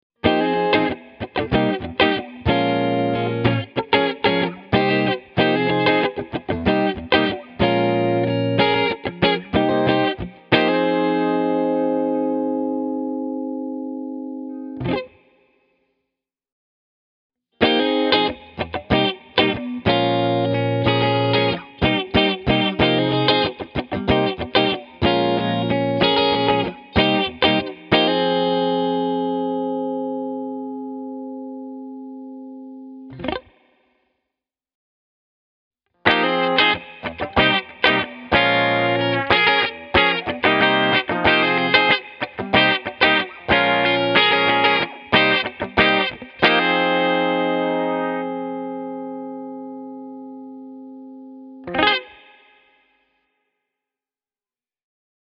The acoustic tone is a good deal more open, and it displays a lovely vocal mid-range timbre, as well as a slightly lighter bass response.
Tokai’s fine PAF-Vintage Mk3 -humbuckers work exceedingly well in this context, because these vintage-type, moderately-powered pickups have an open and dynamic sound, and don’t clog up the guitar’s mid-range frequencies.
Here’s a clean clip: